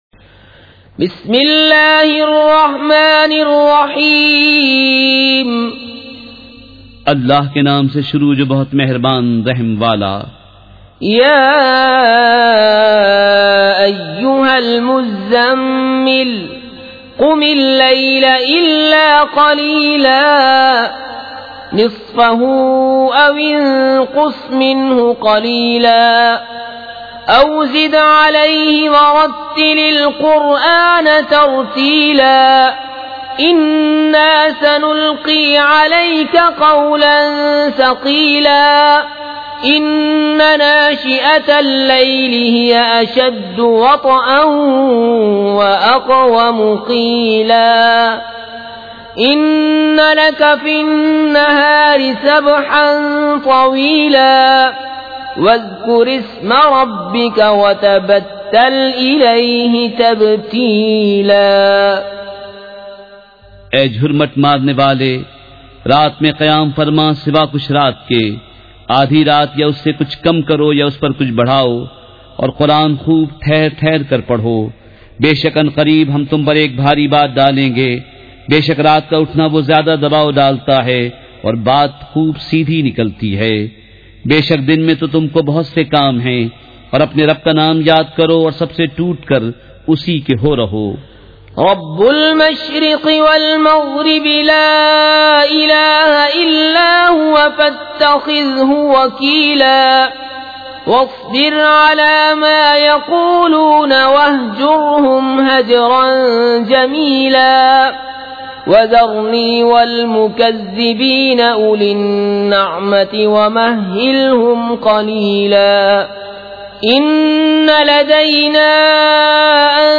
سورۃ المزّمّل مع ترجمہ کنزالایمان ZiaeTaiba Audio میڈیا کی معلومات نام سورۃ المزّمّل مع ترجمہ کنزالایمان موضوع تلاوت آواز دیگر زبان عربی کل نتائج 1744 قسم آڈیو ڈاؤن لوڈ MP 3 ڈاؤن لوڈ MP 4 متعلقہ تجویزوآراء